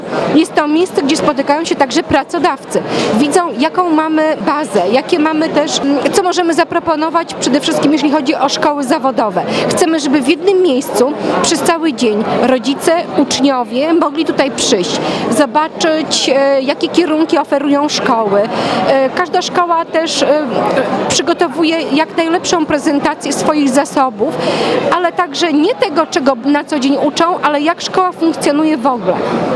– Dzięki targom uczniowie i rodzice mogą w jednym miejscu poznać ofertę szkół – powiedziała Radiu 5 Ewa Sidorek, zastępca prezydenta Suwałk.